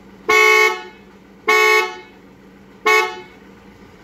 7. Звук сигнала, клаксона Волги ГАЗ 24
gaz24-signal.mp3